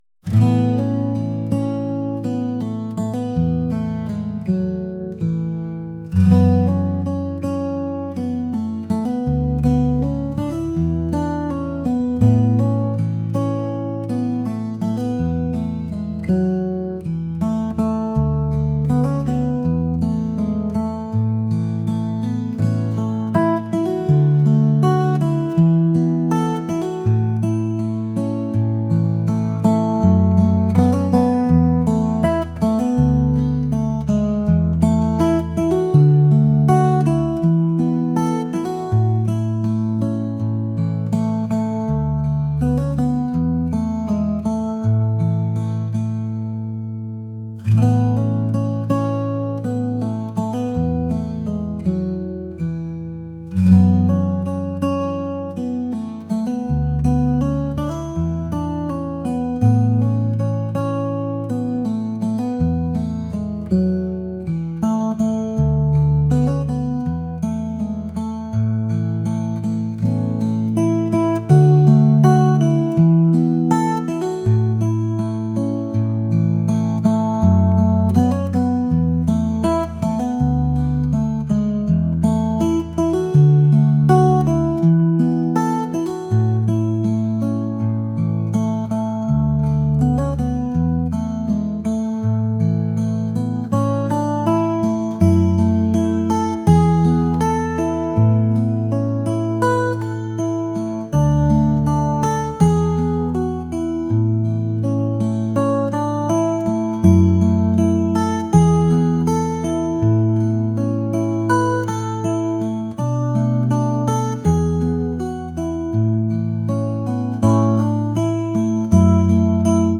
acoustic | pop | indie